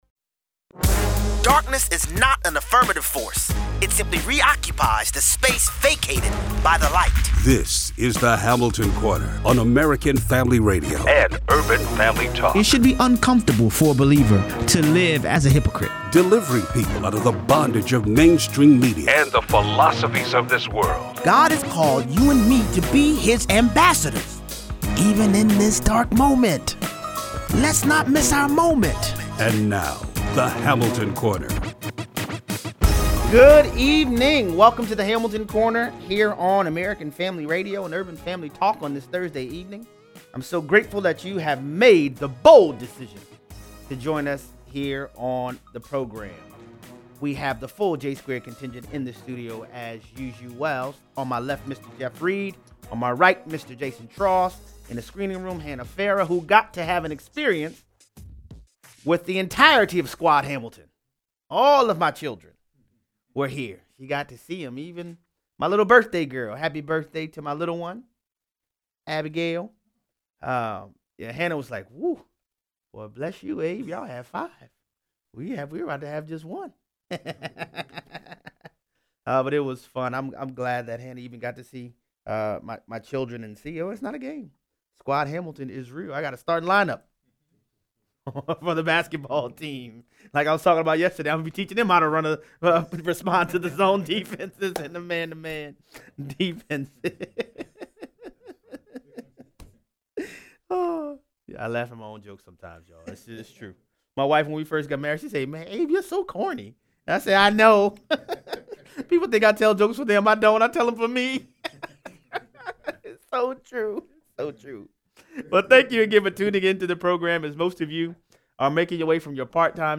0:18 - 0:35: While the media spends its time discussing “handsy Joe” it seems the former Vice President has a much bigger Ukrainian problem. 0:38 - 0:55: Christiane Amanpour reveals her disdain for the freedom of speech. Callers weigh in.